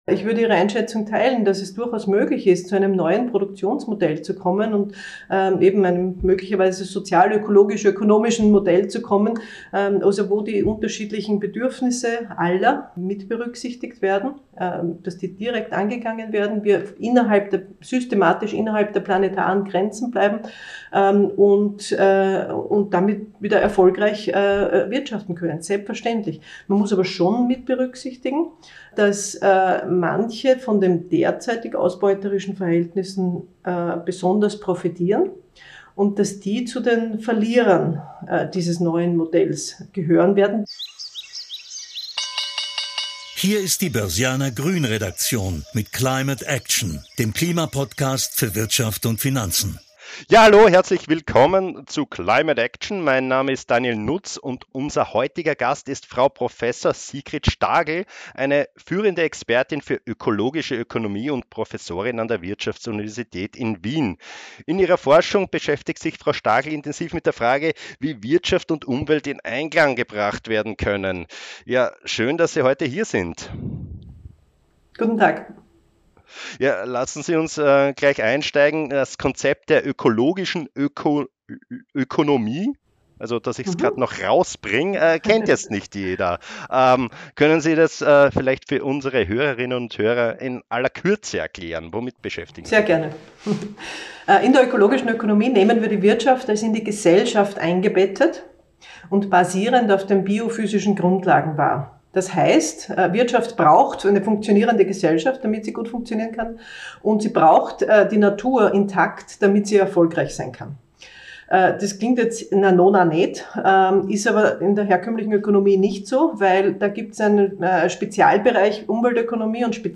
Im aktuellen Podcast sprechen wir mit ihr darüber, wie diese neue Denkweise bei der Betrachtung des Wirtschaftssystems helfen kann, die Transformation in Richtung Klimaziele zu unterstützen.